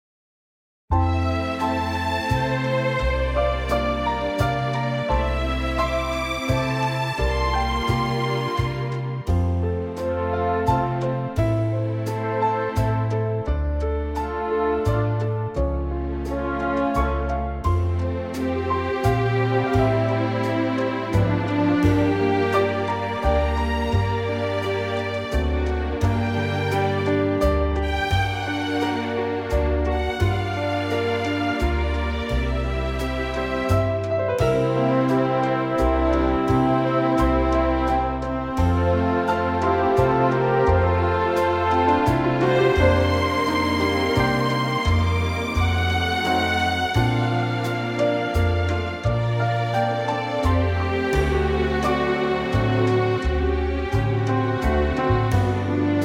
Unique Backing Tracks
key - F - vocal range - C to D
Gorgeous orchestral arrangement of this lovely waltz.